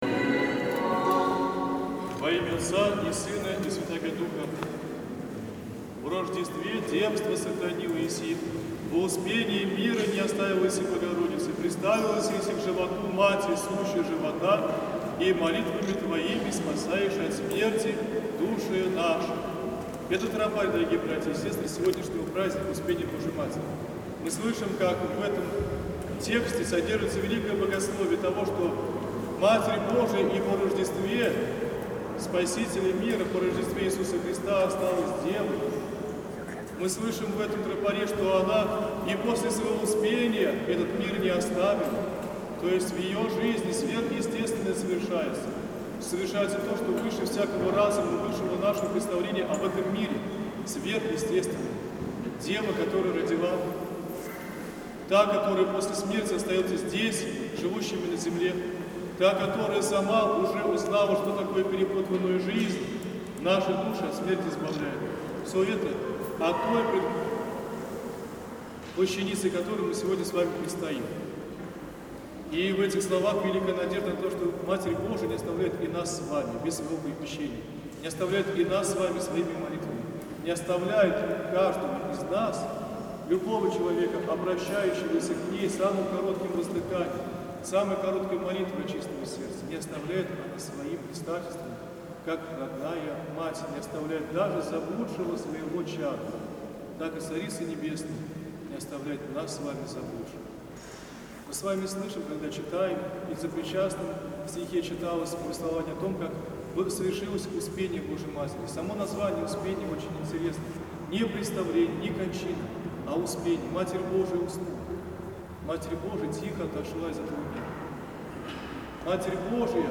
В храме святых Мефодия и Кирилла состоялись богослужения в честь праздника Успения Пресвятой Владычицы нашей Богородицы и Приснодевы Марии